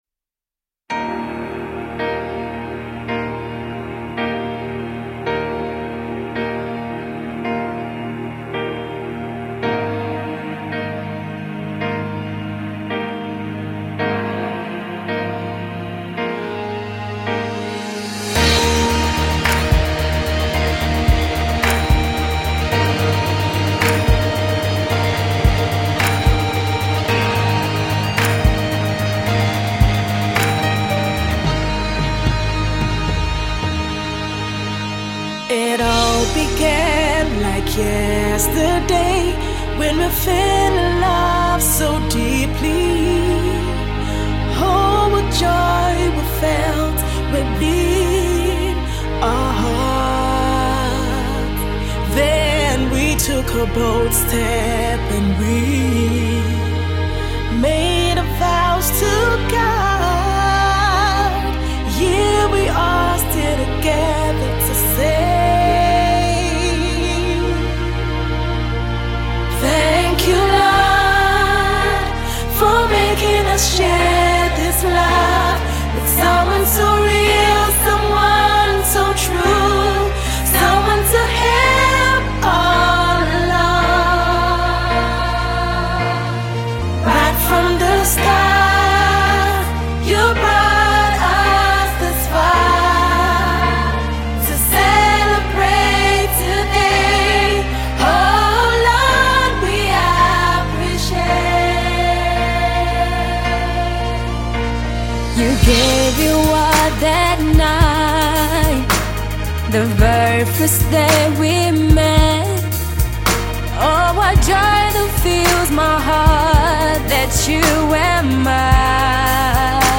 girl group